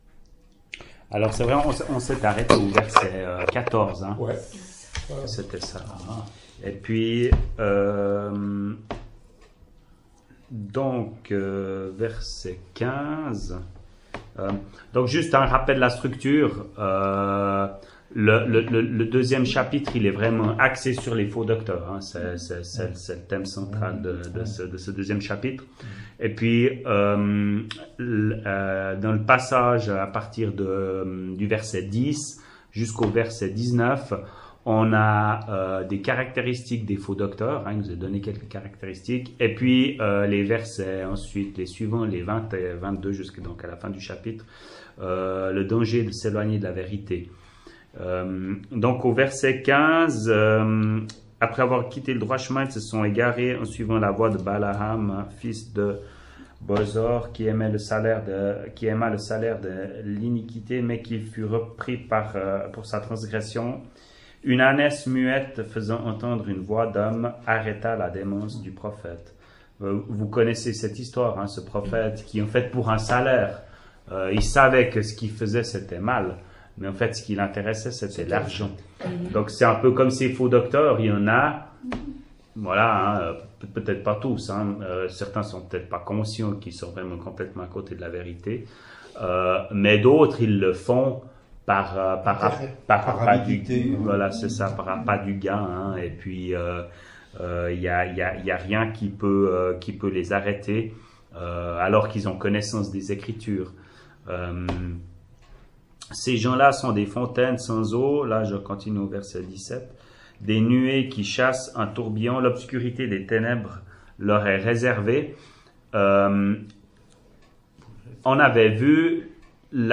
[Chapelle de l’Espoir] - Étude biblique : Deuxième Épître de Pierre, 7ième partie